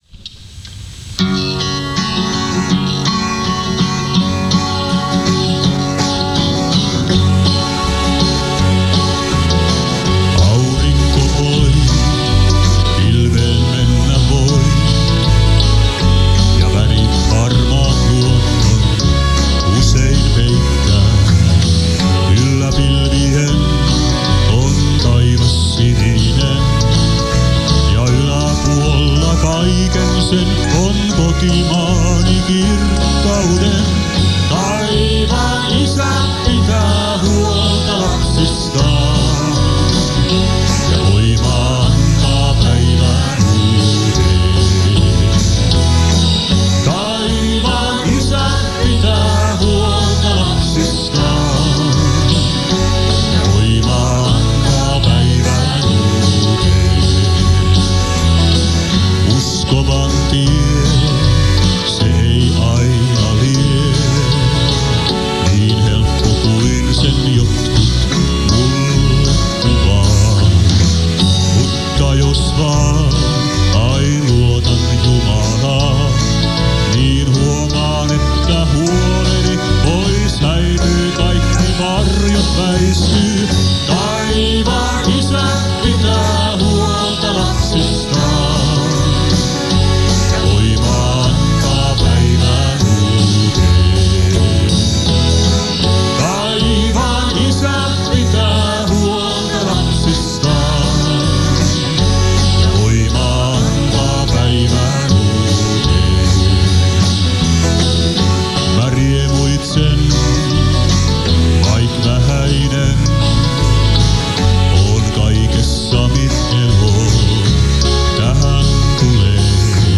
* Gospelbändi Synsygus * (kasetin etu- ja sisäkansi)
* Synsygus live *
Honkajoen yhteiskoululla, Kurikassa ja Merikarvialla (1991-1992)
Honkajoen yhteiskoululla 1991 (uusi versio!)